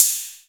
Ride808.wav